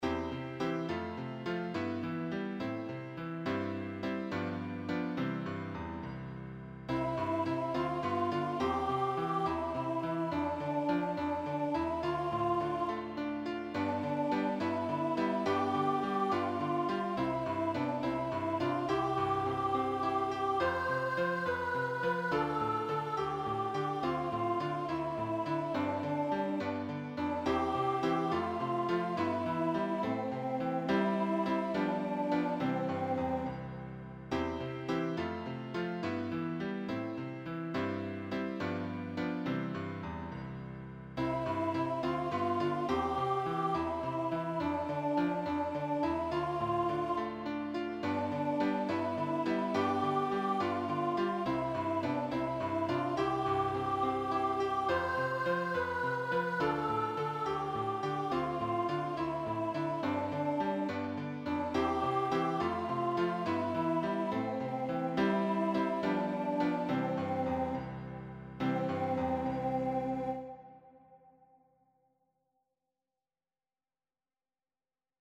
A collection of 15 uplifting and Christ-glorifying children’s choruses covering a wide range of Biblical topics.